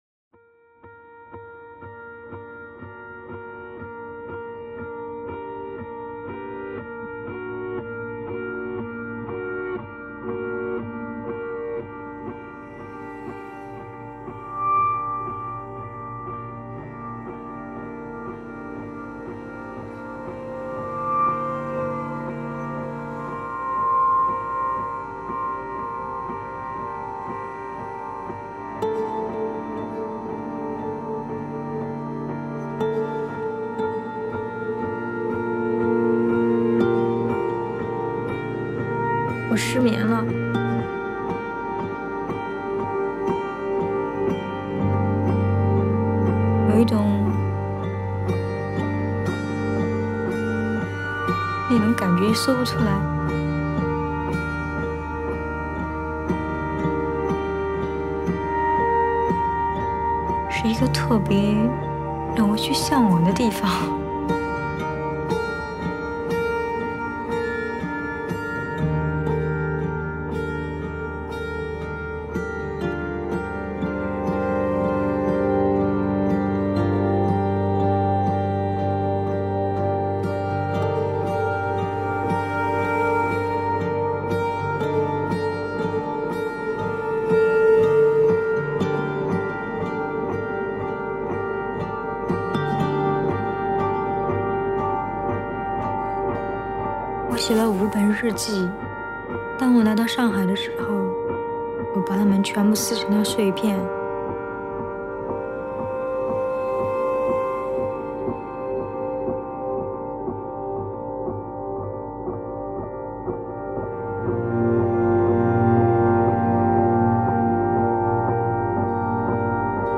Electronica.